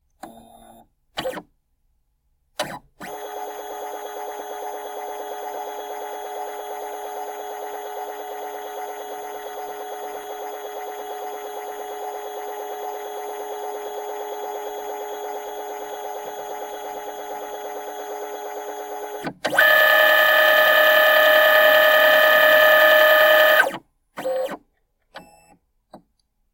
プリンタ・スキャナ